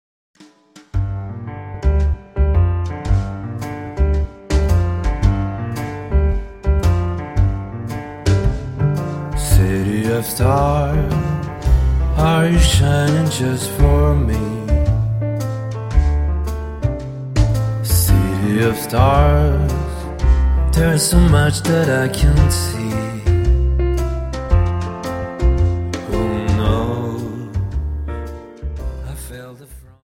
Dance: Slowfox 29 Song